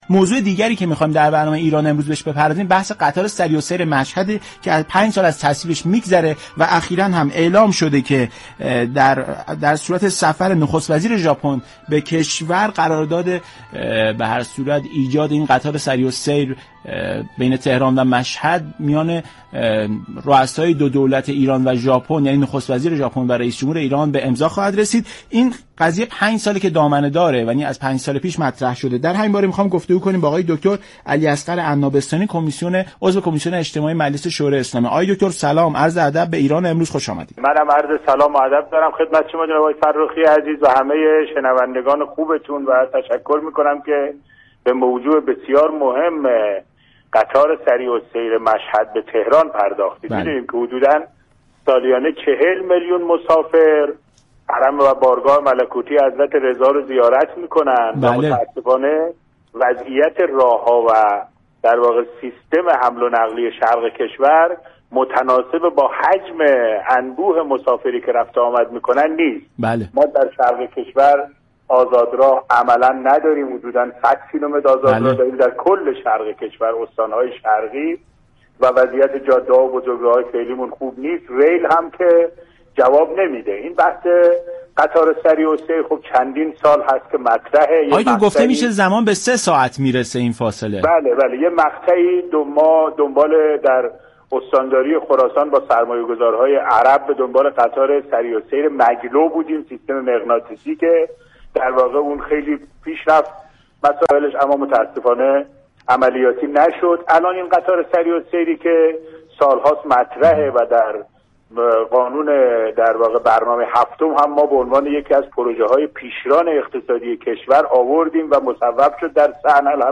دكتر علی اصغر عناب ستانی عضو كمیسیون اجتماعی مجلس شورای اسلامی در برنامه «ایران امروز» بیان كرد: با توجه به قطار سریع السیر مشهد و توجه به آزاد راه ها در شرق كشور به دنبال منافع كشور از طریق وصل چابهار به غرب كشور هستیم.